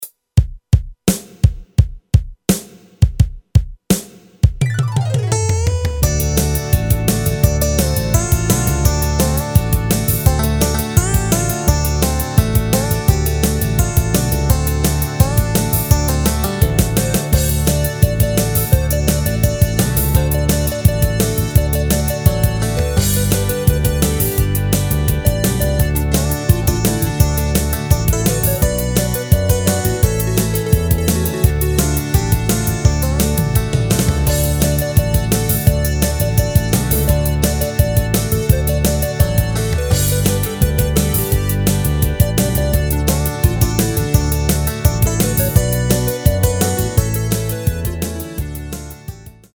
Rubrika: Pop, rock, beat
Nejnovější MP3 podklady